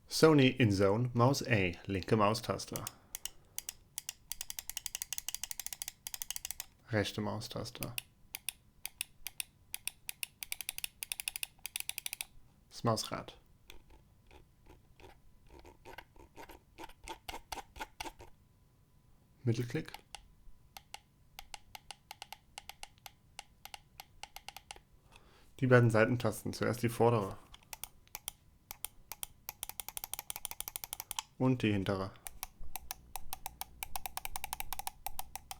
Zu den Primärtastern lässt sich ergänzen, dass sie zwar ziemlich laut sind, dafür aber immerhin angenehm taktil und fast ohne Pretravel ausfallen. Das Mausrad wiederum ist vergleichsweise leise, aber für eine präzise Bedienung trotzdem ausreichend deutlich gerastert.